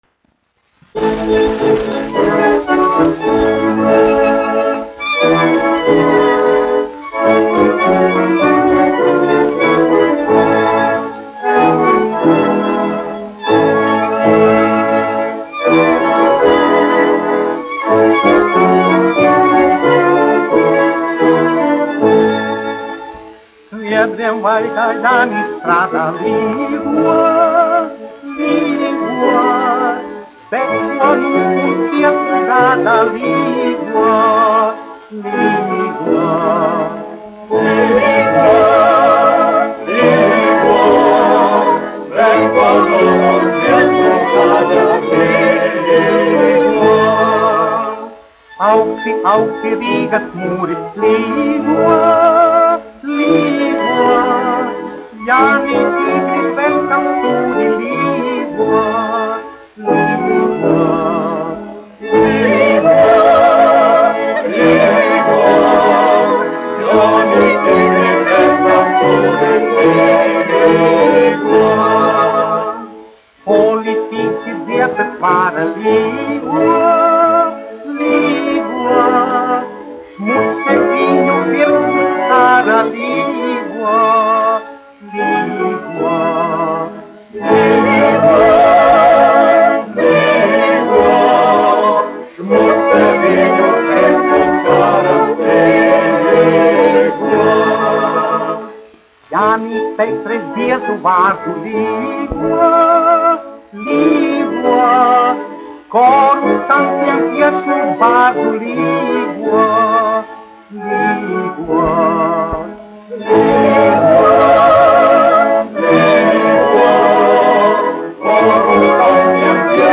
1 skpl. : analogs, 78 apgr/min, mono ; 25 cm
Humoristiskās dziesmas
Latvijas vēsturiskie šellaka skaņuplašu ieraksti (Kolekcija)